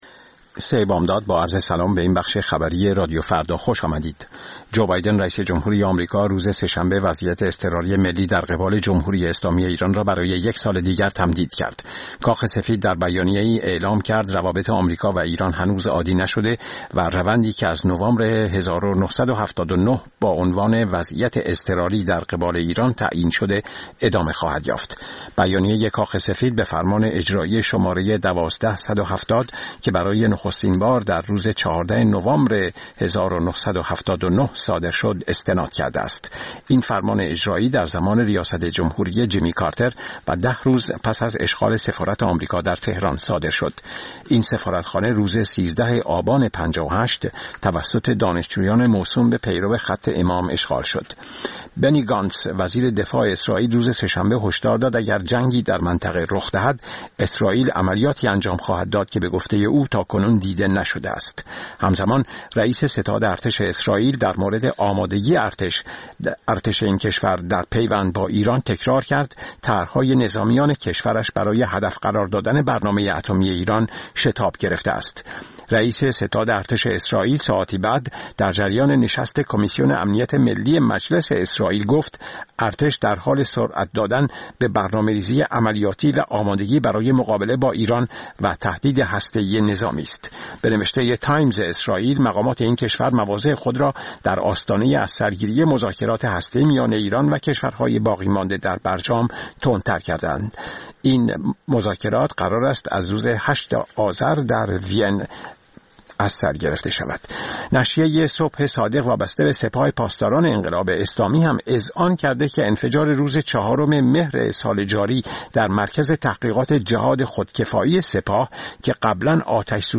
سرخط خبرها ۳:۰۰